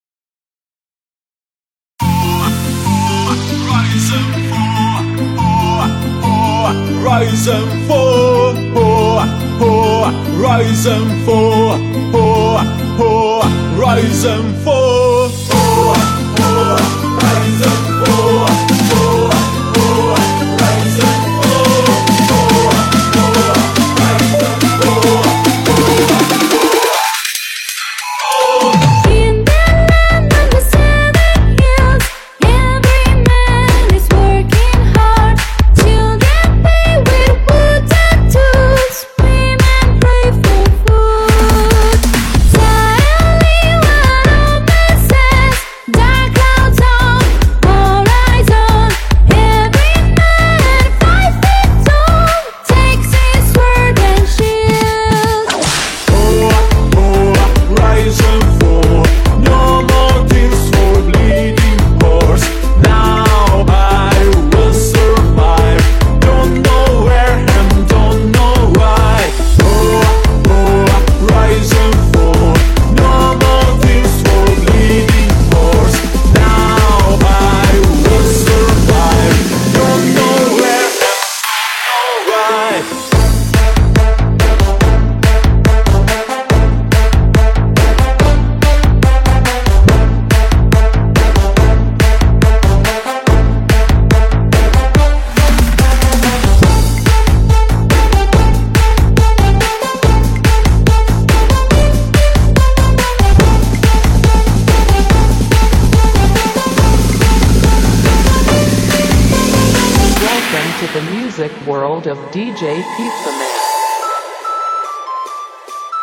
越南VinaHouse